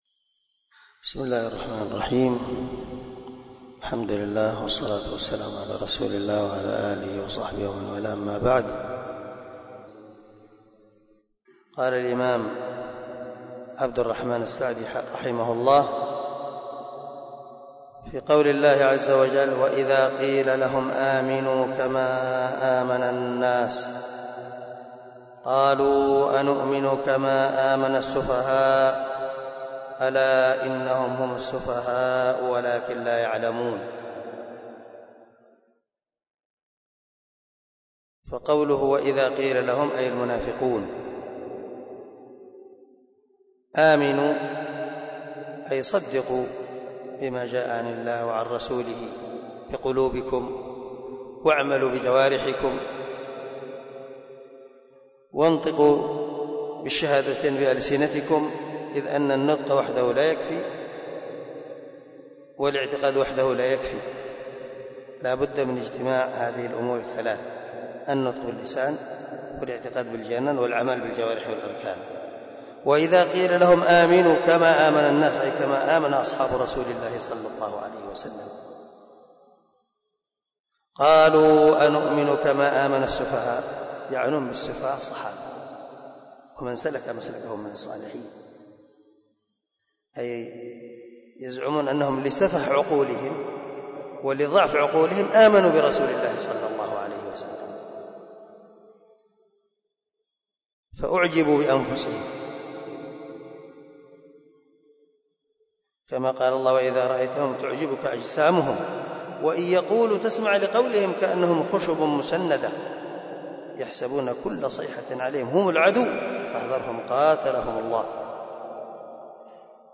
015الدرس 5 تفسير آية ( 13 ) من سورة البقرة من تفسير القران الكريم مع قراءة لتفسير السعدي